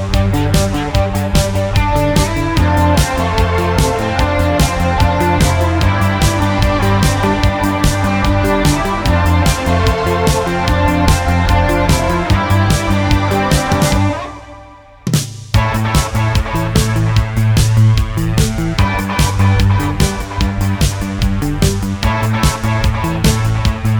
Cut Down with No Backing Vocals Pop (1980s) 3:41 Buy £1.50